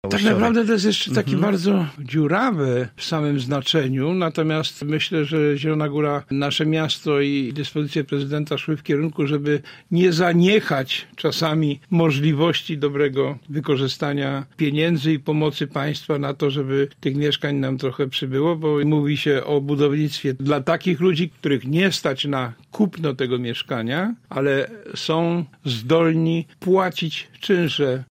Sprawę skomentował Andrzej Bocheński, radny Zielonej Razem:
Andrzej Bocheński był gościem Rozmowy po 9.